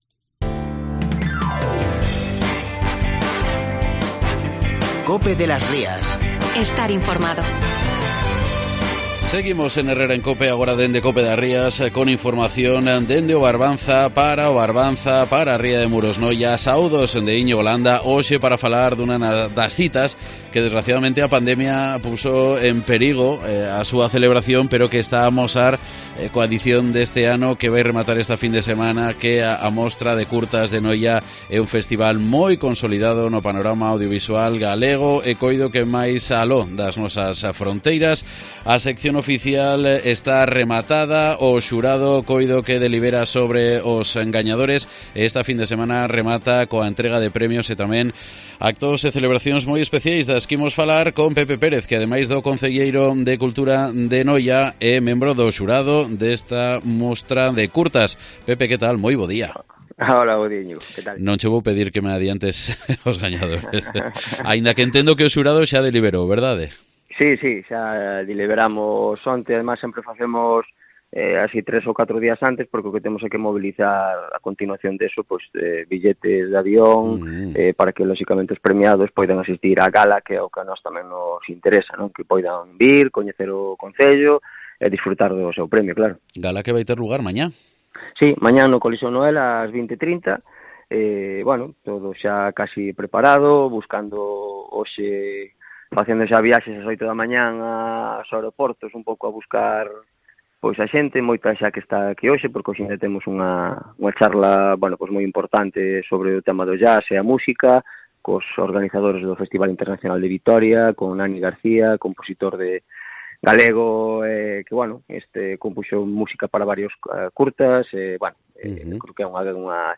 Entrevista en COPE de las Rías con la actriz Rosario Pardo y el edil de cultura de Noia, Pepe Pérez